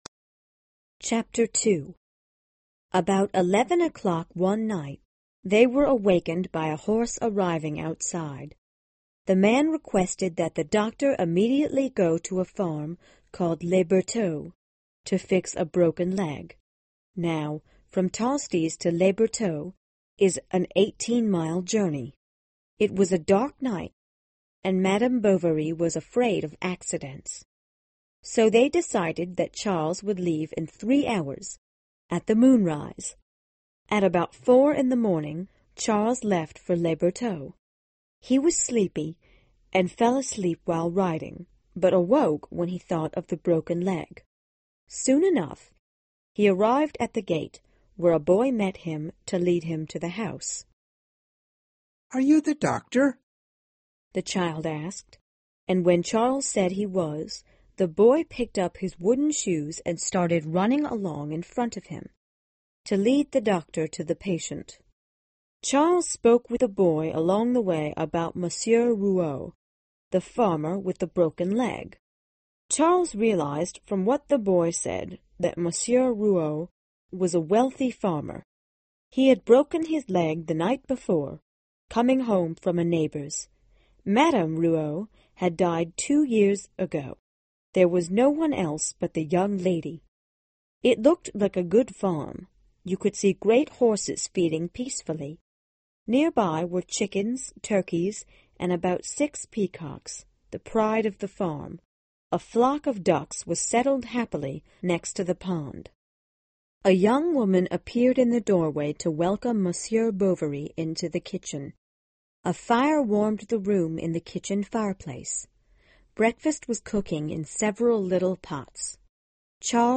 在线英语听力室有声名著之包法利夫人 02的听力文件下载,包法利夫人-在线英语听力室